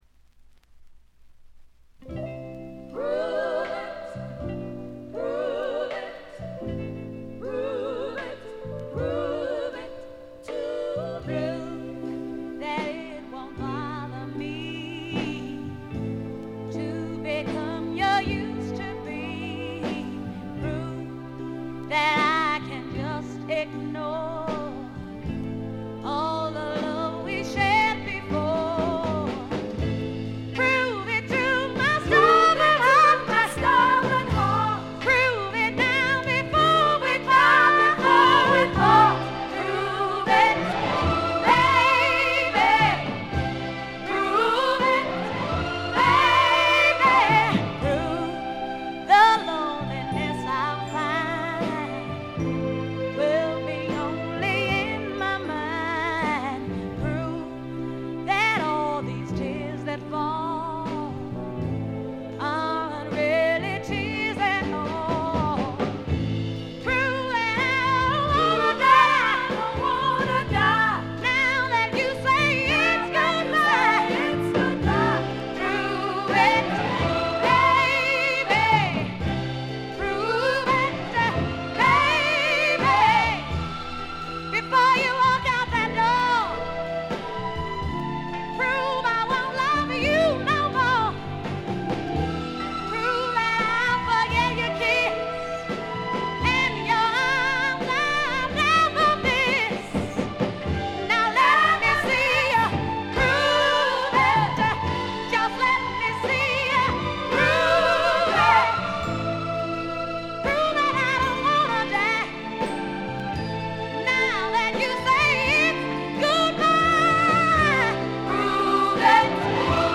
ほとんどノイズ感無し。
試聴曲は現品からの取り込み音源です。
vocals, piano